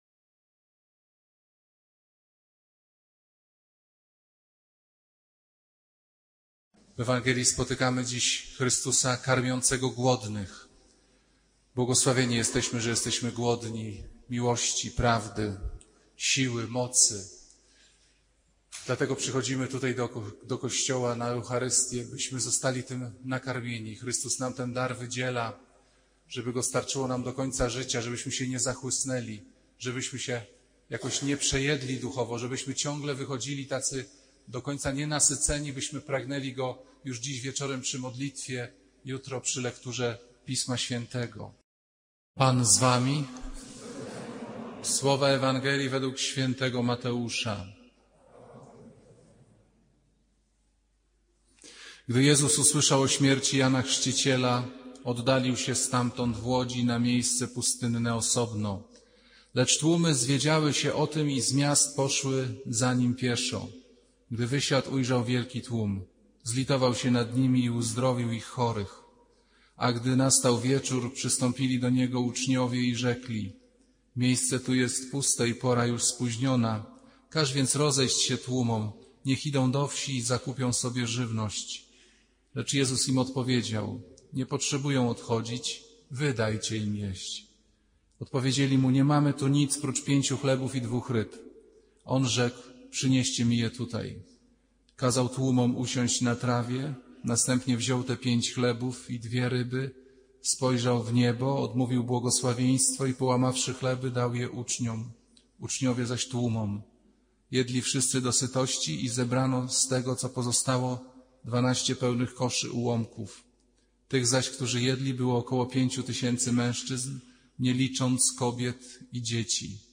Kazania księdza Pawlukiewicza o szaleństwie miłości Boga.